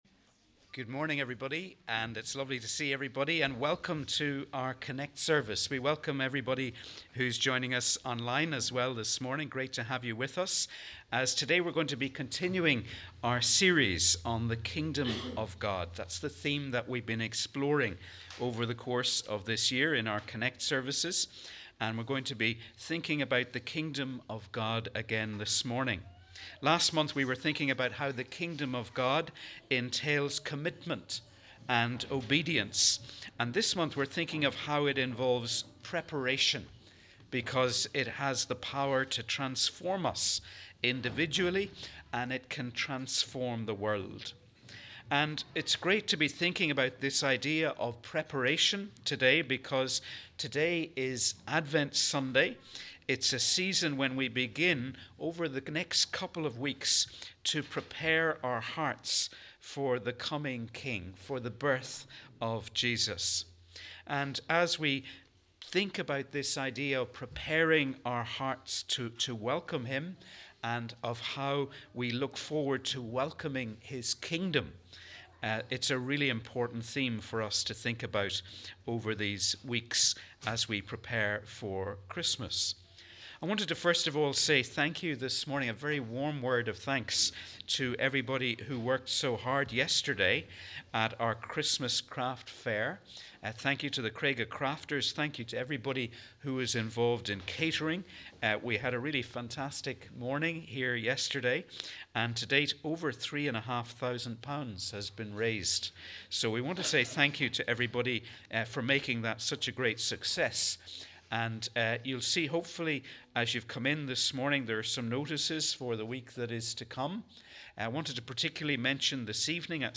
3rd December – Advent Sunday Connect Service
We warmly welcome you to our Connect service on this Advent Sunday, the beginning of the new Christian Year.